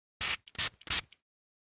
perfume2.ogg